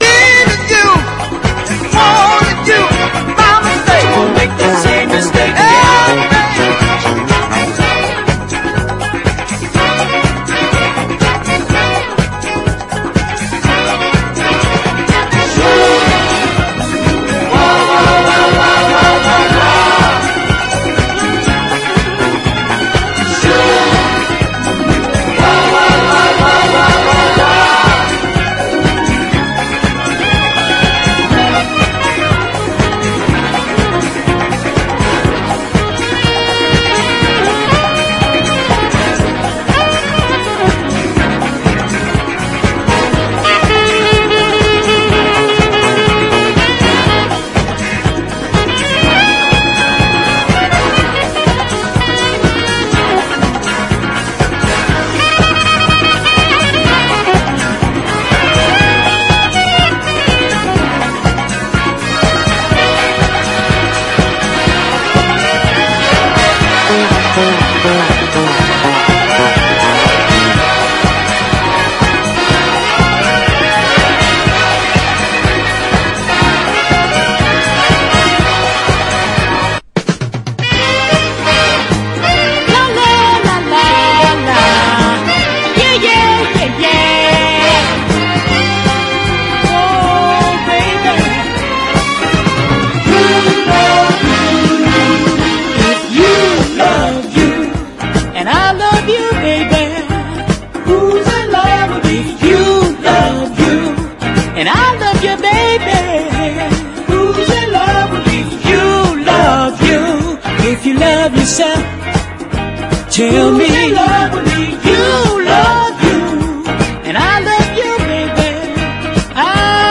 JAZZ / MAIN STREAM / BIG BAND
もゴージャスなショウビズ・スウィング！